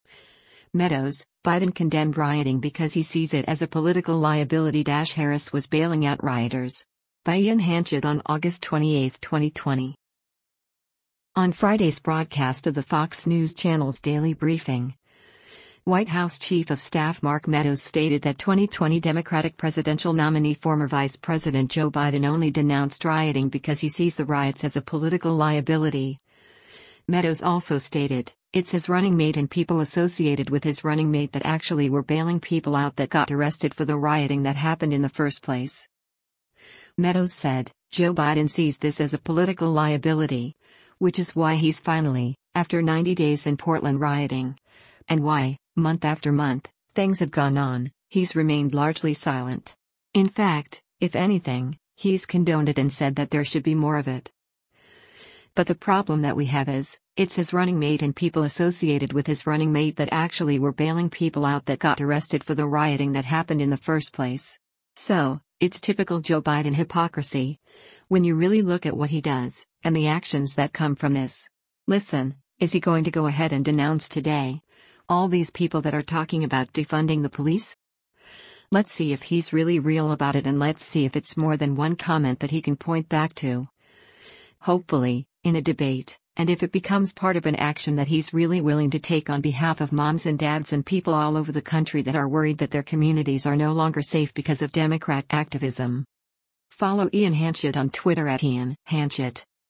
On Friday’s broadcast of the Fox News Channel’s “Daily Briefing,” White House Chief of Staff Mark Meadows stated that 2020 Democratic presidential nominee former Vice President Joe Biden only denounced rioting because he sees the riots “as a political liability,” Meadows also stated, “it’s his running mate and people associated with his running mate that actually were bailing people out that got arrested for the rioting that happened in the first place.”